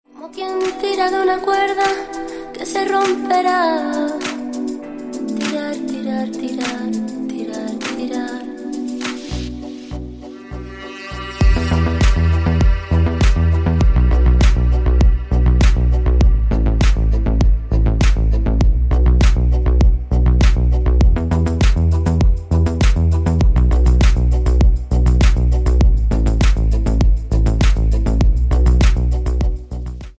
Латинские Рингтоны
Танцевальные Рингтоны